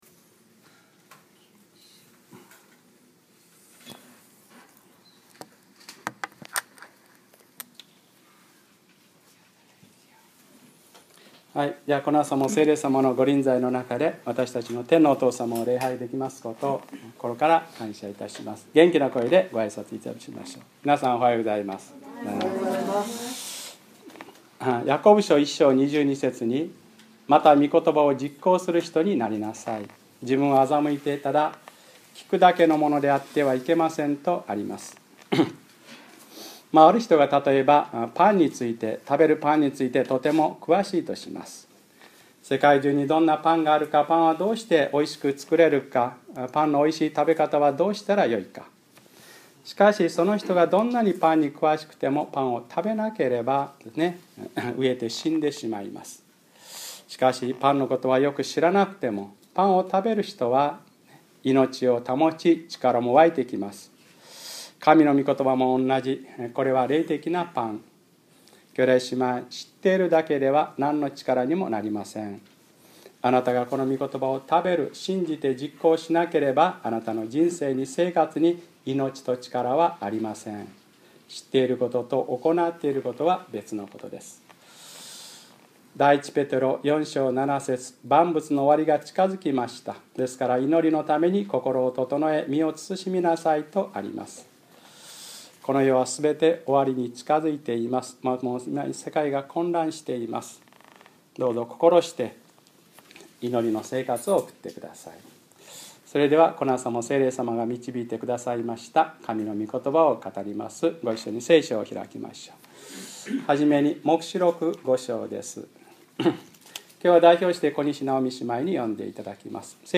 2012年6月24日(日）礼拝説教 『黙示録9/ 5章1節より』